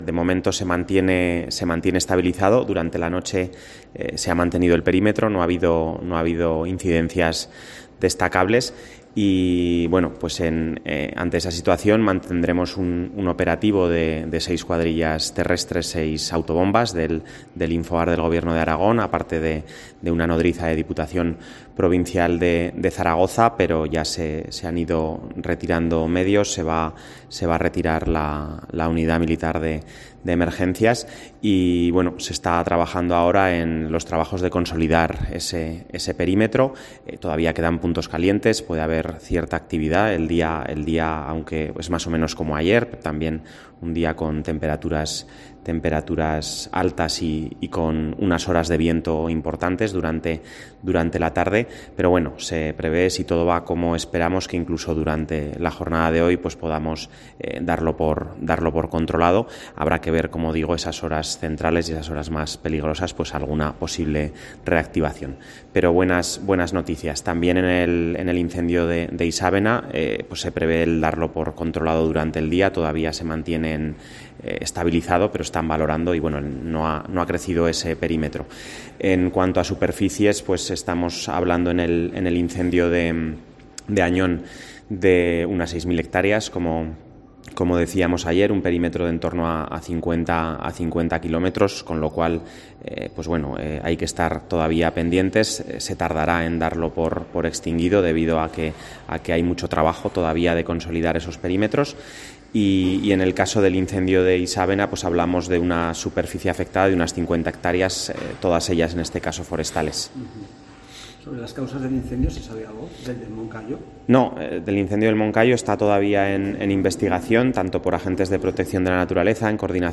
Tras la reunión del CECOPI, el director de Gestión Forestal, Diego Bayona, explica cómo está la situación.